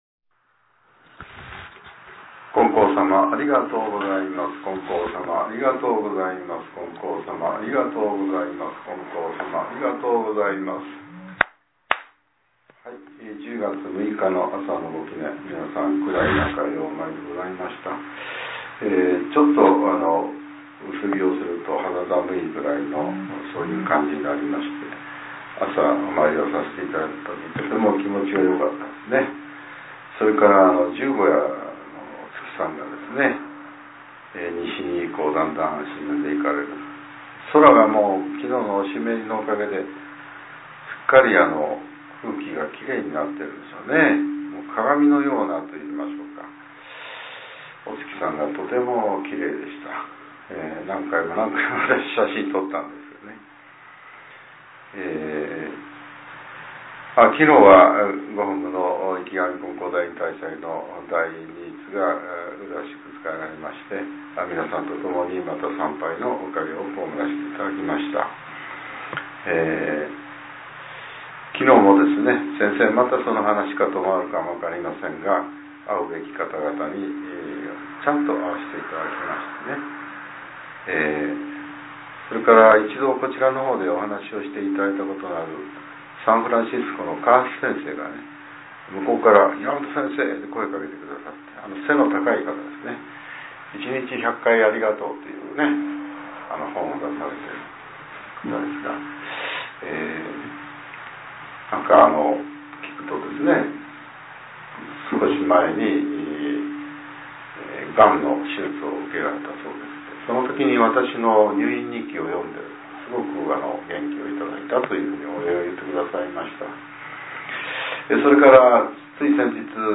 令和７年１０月６日（朝）のお話が、音声ブログとして更新させれています。